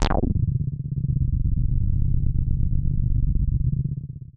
G1_moogy.wav